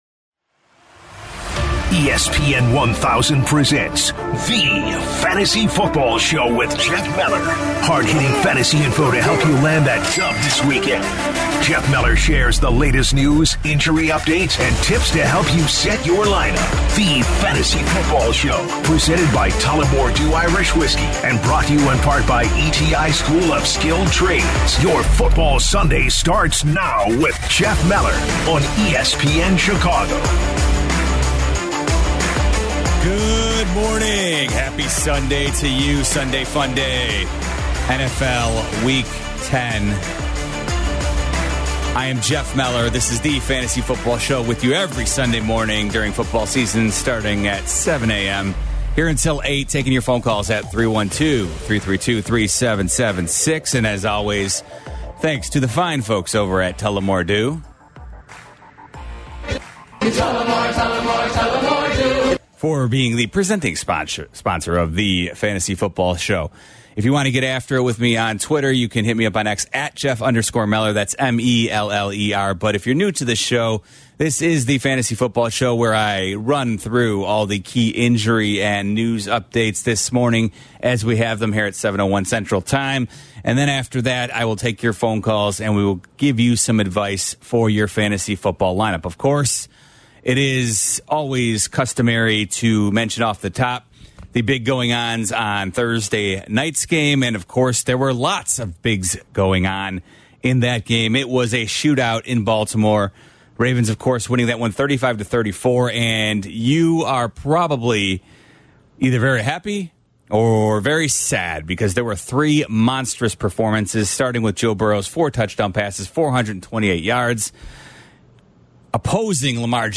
then answers calls as he helps guide them through their toughest start and sit questions for Week 10